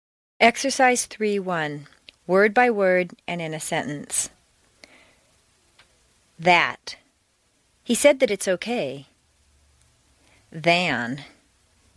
在线英语听力室美式英语正音训练第58期:练习1&2&3&4&5的听力文件下载,详细解析美式语音语调，讲解美式发音的阶梯性语调训练方法，全方位了解美式发音的技巧与方法，练就一口纯正的美式发音！